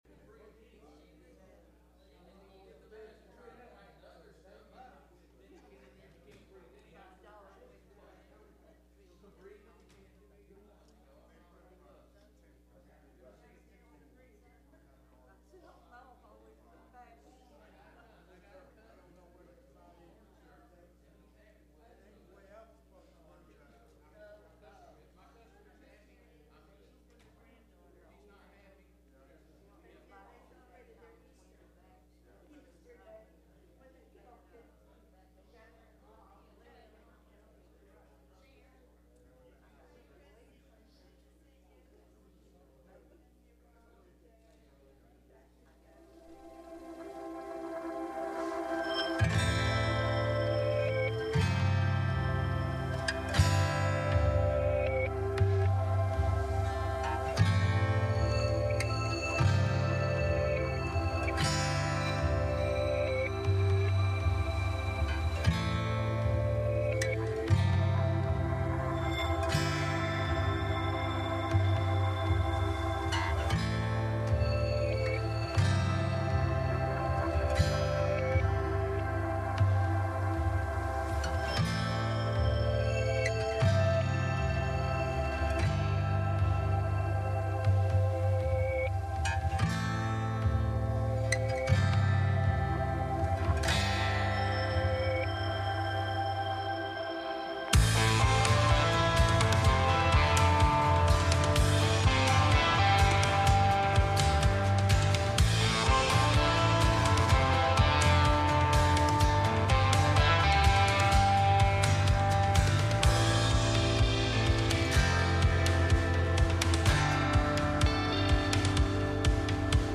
Message Service Type: Sunday Morning https